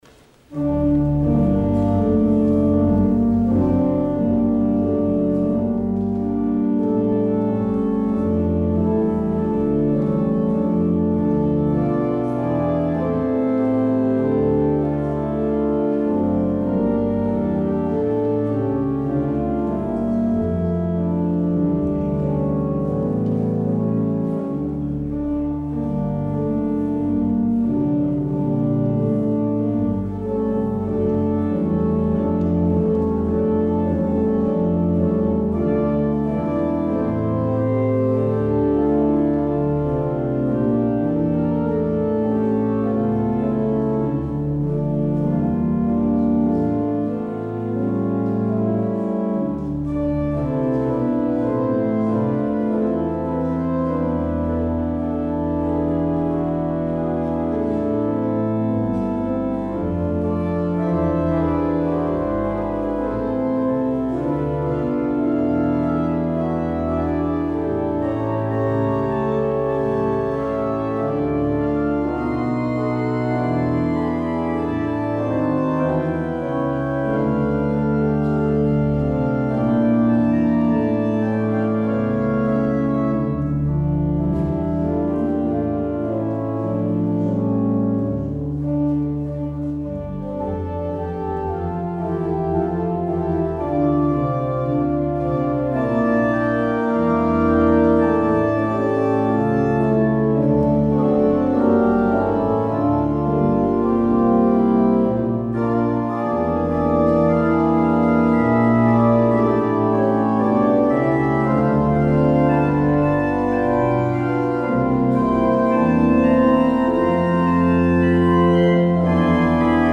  Luister deze kerkdienst hier terug: Alle-Dag-Kerk 8 oktober 2024 Alle-Dag-Kerk https